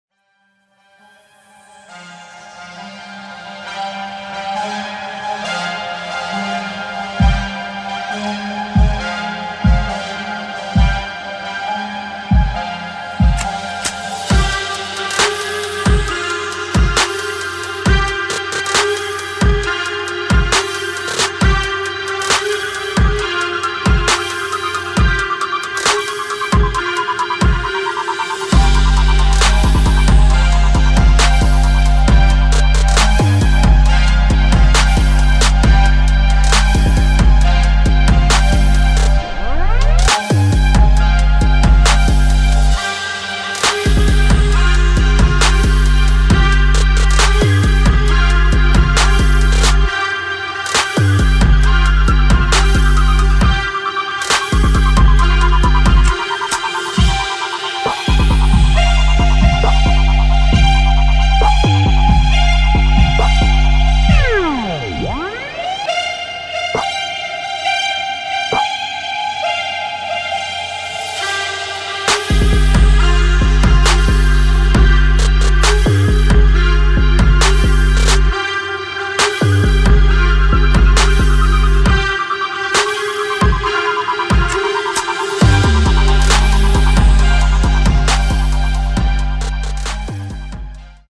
[ DUBSTEP / DRUM'N'BASS ]